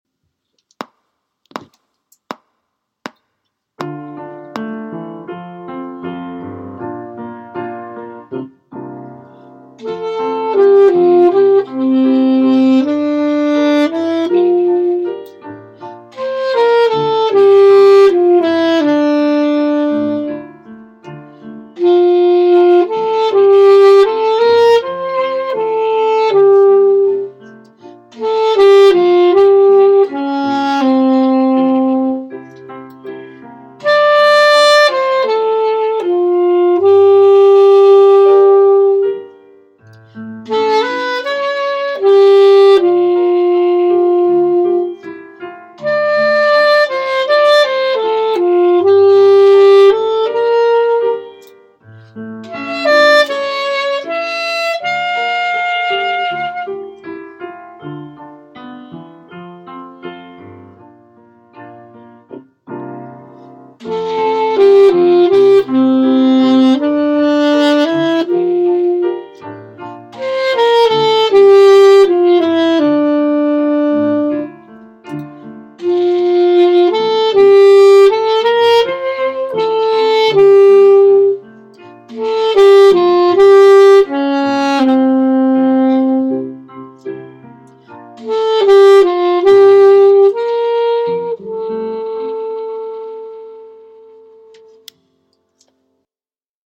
Good To Be Back piano